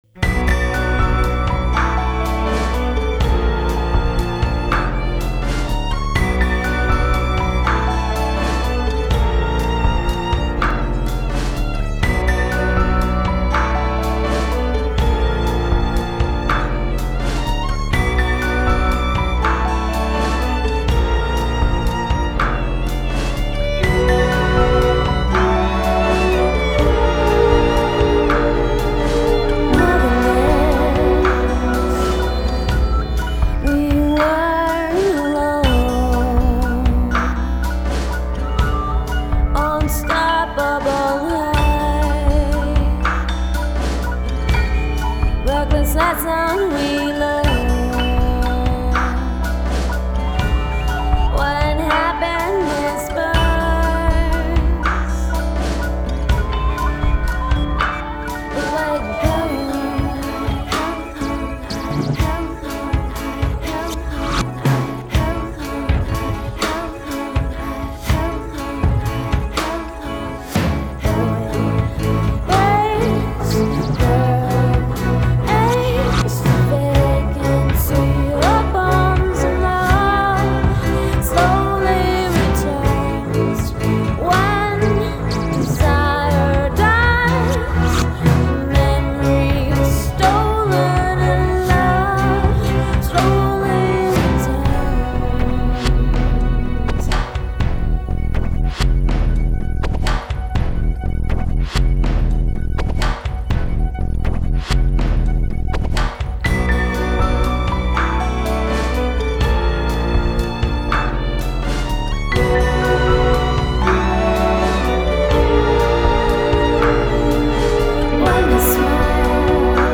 vocals, acoustic and electric guitars, drum pads, keyboards.
viola, violin.
upright bass
cello